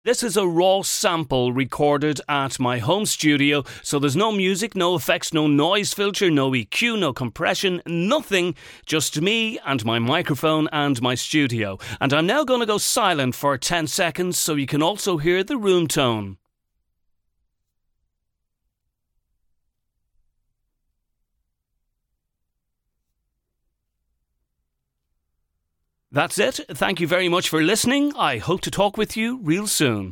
Commercial
Male
40s, 50s, 60s
British English (Native)
Authoritative, Confident, Corporate, Engaging, Friendly, Natural, Reassuring, Warm
Microphone: Rode NT1A, RODE NT2A, Neumann TLM103